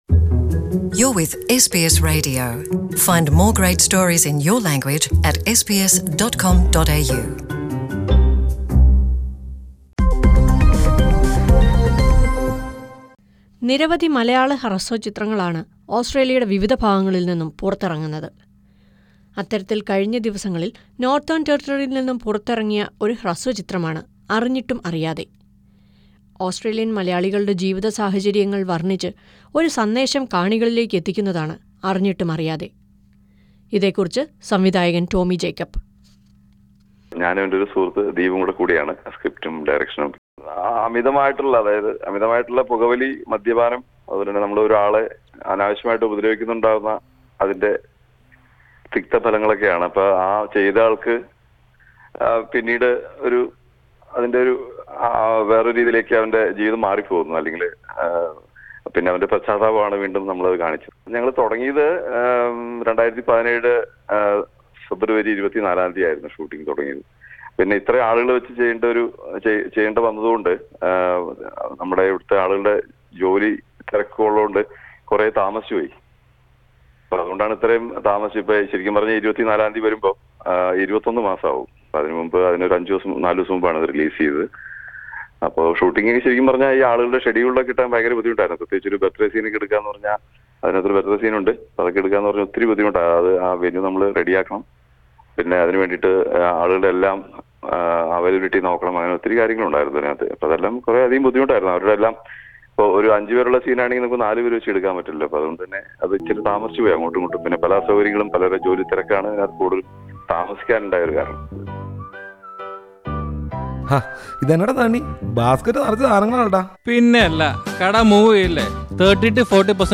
Listen to a report on the two Malayalam short films- Arinjittum Ariyathe and Kerala PO being released from Australia.